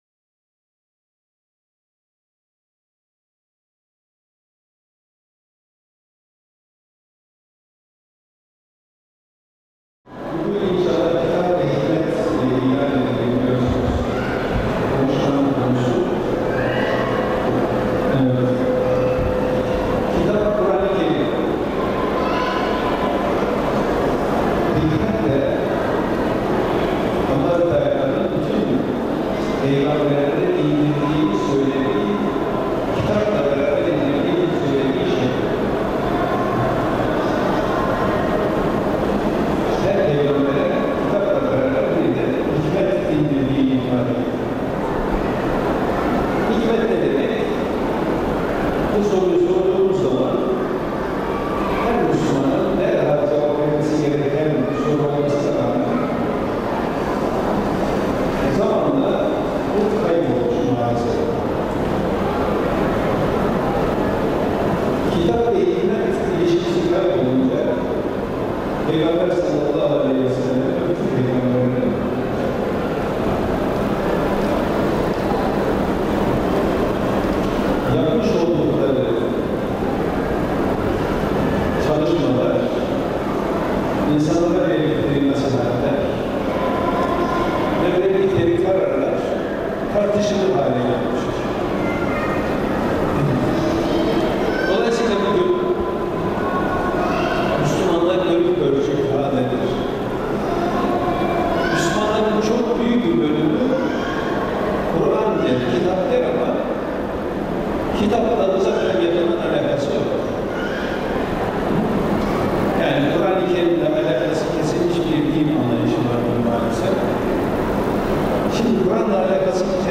Konferanslar